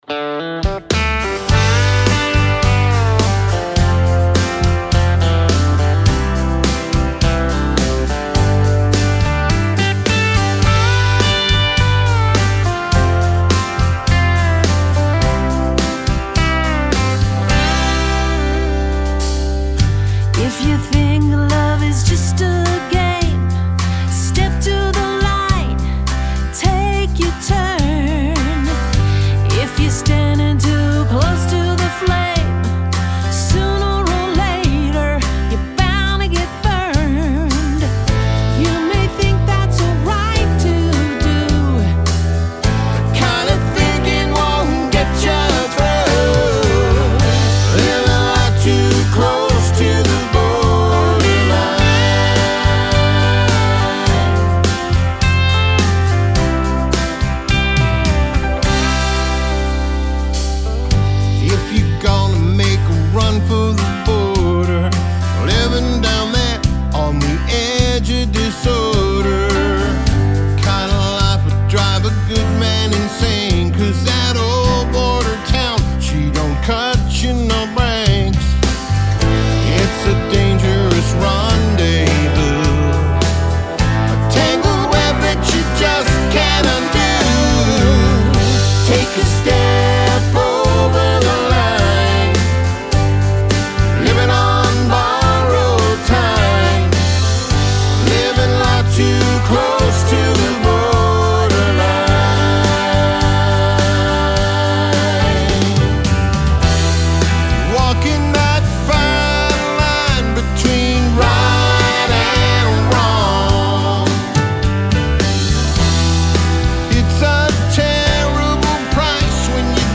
slick harmonies & hard-hitting vocals